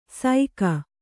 ♪ saika